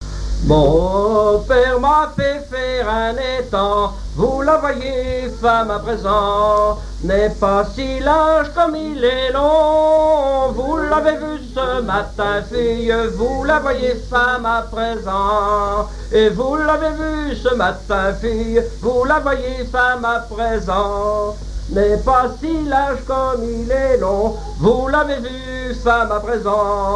Vendée
Genre laisse
Pièce musicale inédite